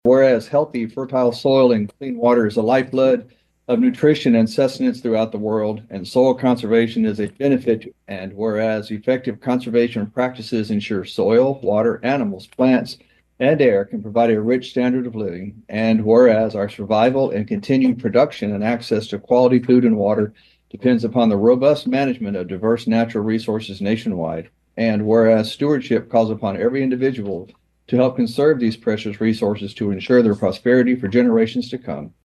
Precinct 3 Commissioner Bill Daugette read a proclamation in court.